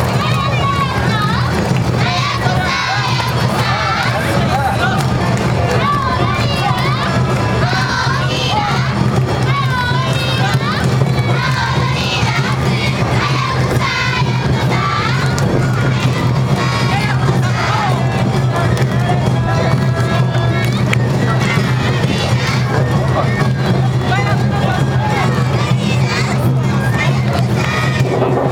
−　阿波踊り　−
街中ではどこからともなく阿波踊りの音楽が